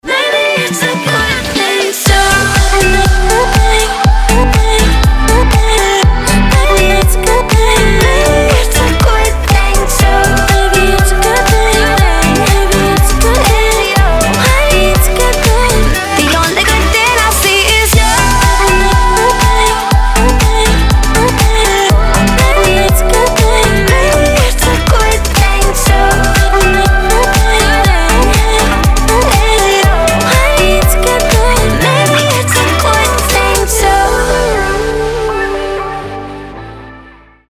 красивые
женский вокал
dance
Electronic